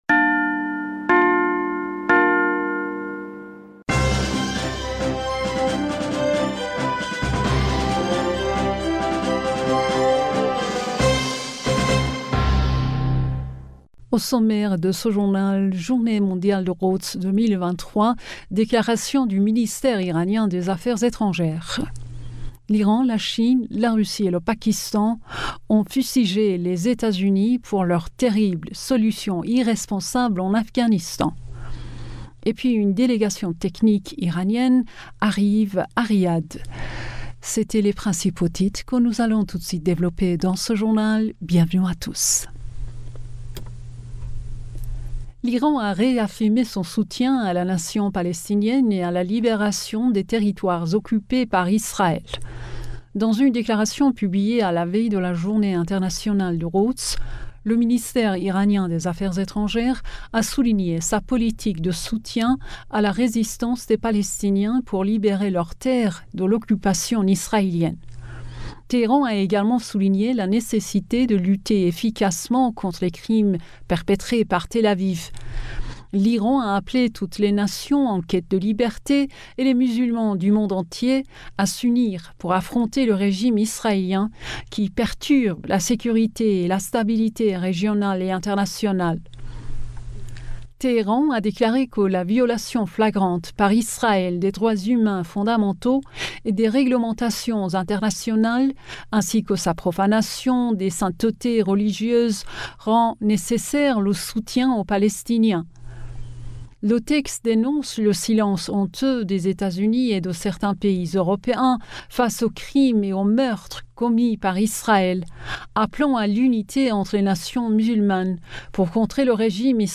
Bulletin d'information du 13 Avril 2023